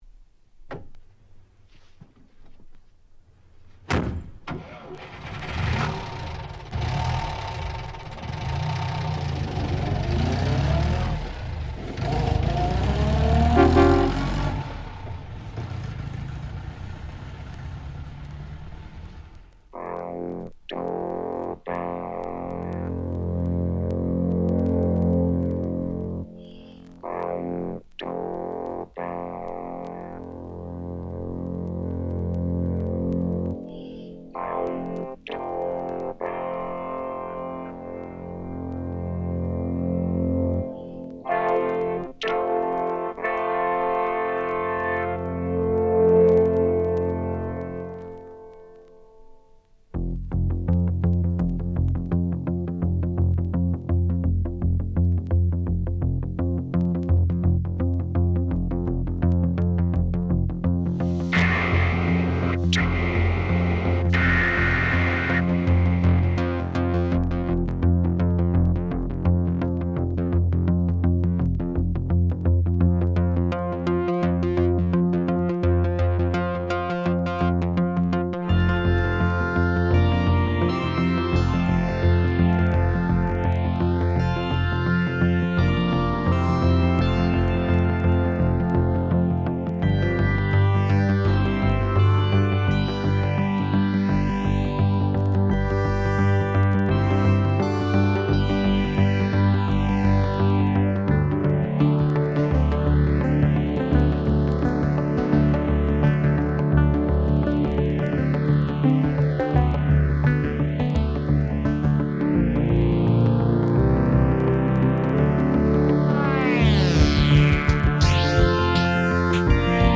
Genre: Classic Electronic.